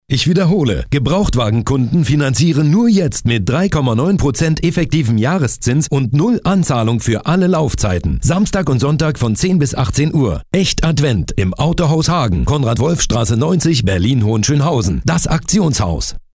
Radio: ALLE MAL HERHÖREN!